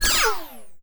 sci-fi_power_down_03.wav